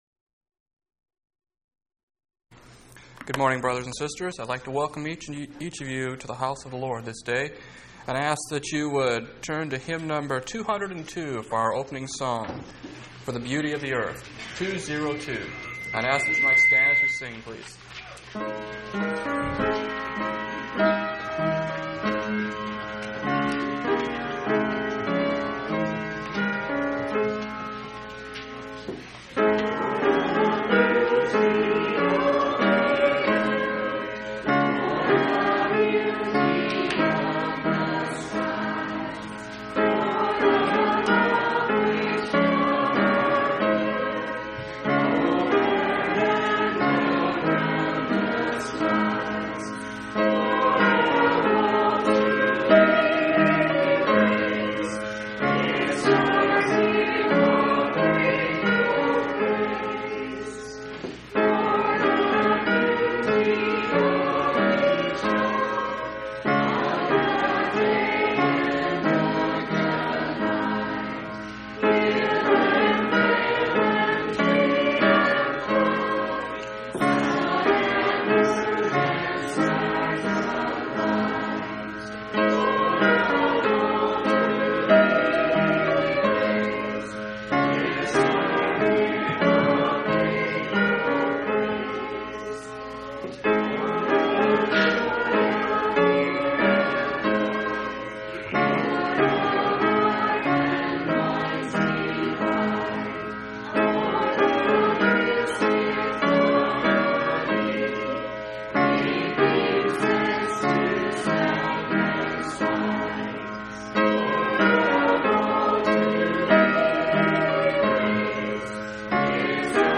10/9/1994 Location: Phoenix Local Event